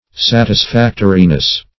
Satisfactory \Sat`is*fac"to*ry\, a. [Cf. F. satisfactoire.]